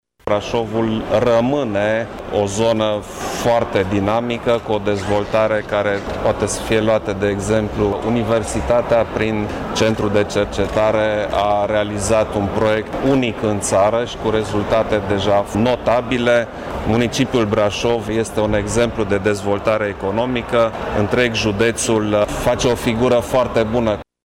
Apoi, preşedintele a ajuns la Aula Universităţii Transilvania, unde a participat la o întâlnire pe teme legate de parteneriatul dintre mediul academic şi industrie în domeniul cercetării şi finanţarea cercetării ştiinţifice româneşti.
Preşedintele a apreciat dinamica Braşovului, înregistrată în ultimul timp în toate domeniile de activitate: